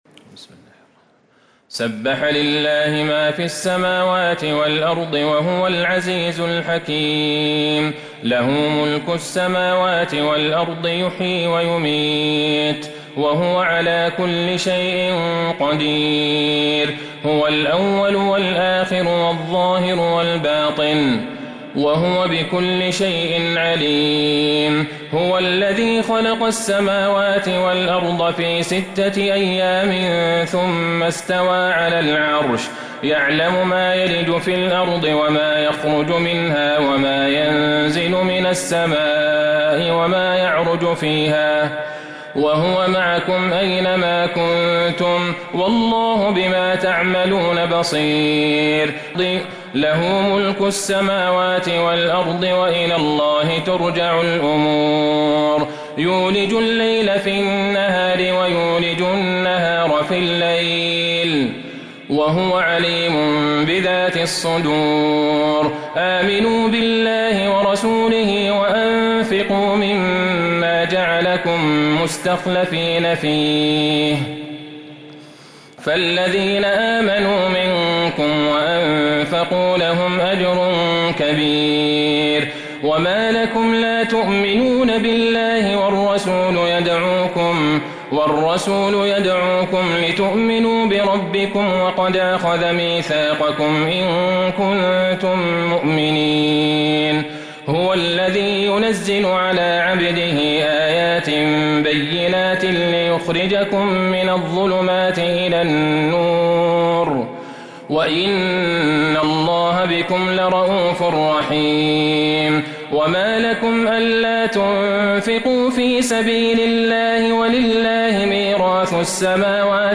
سورة الحديد Surat Al-Hadeed من تراويح المسجد النبوي 1442هـ > مصحف تراويح الحرم النبوي عام ١٤٤٢ > المصحف - تلاوات الحرمين